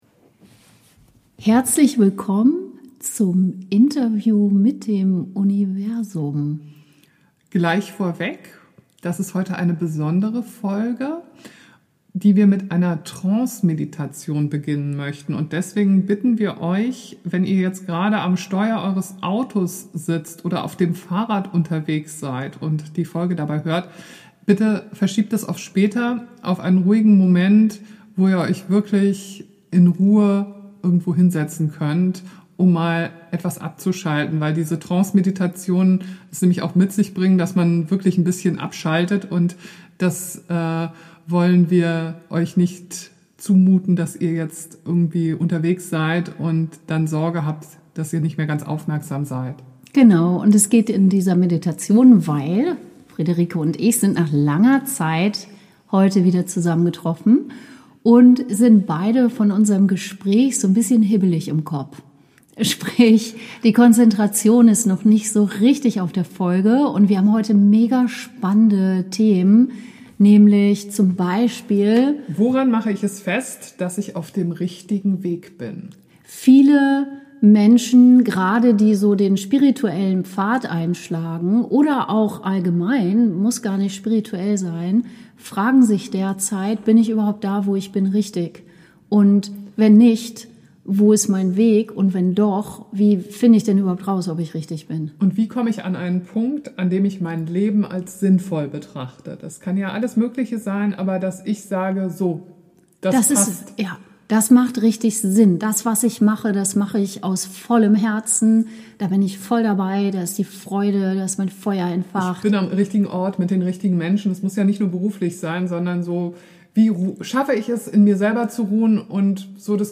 In dieser kurzen Folge nimmt uns das Universum mit in eine angeleitete Trance-Meditation. Achtung: bitte höre diese Folge nicht beim Autofahren, auf dem Fahrrad etc. Suche dir einen ruhigen Platz und schließe die Augen.